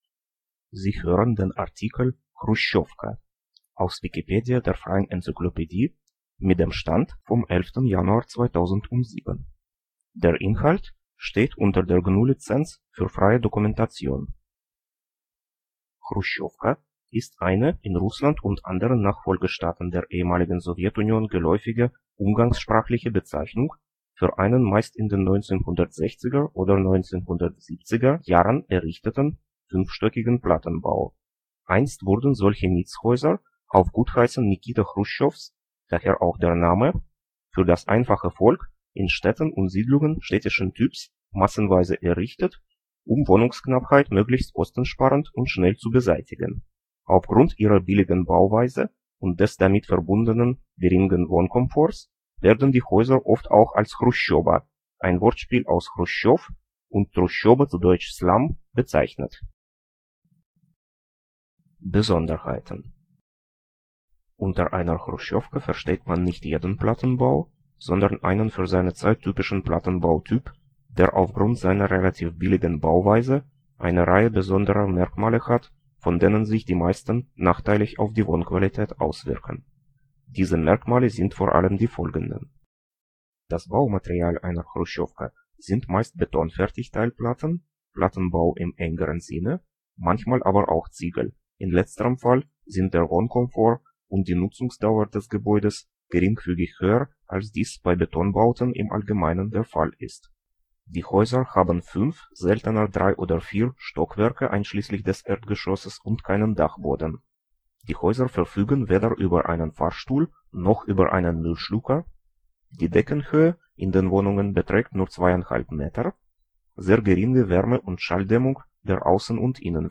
Mehr Informationen zur gesprochenen Wikipedia